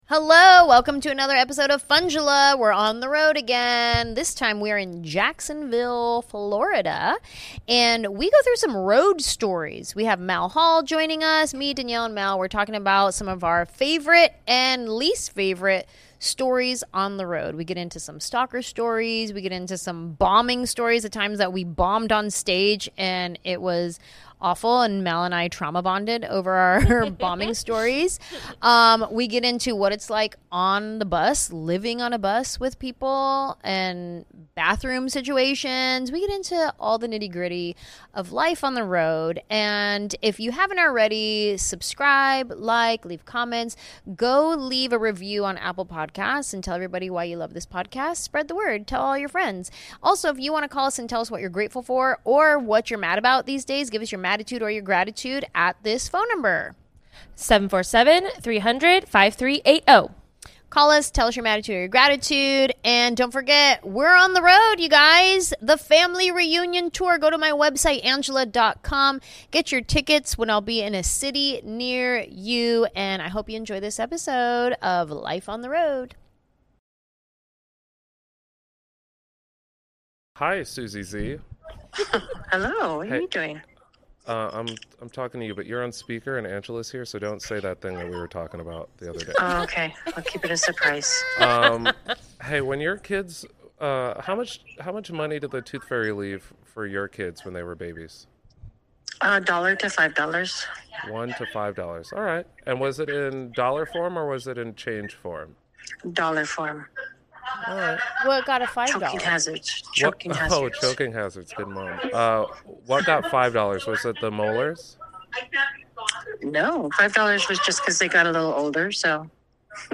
This week on Funjelah, the team is recording from Jacksonville, FL—because tour life!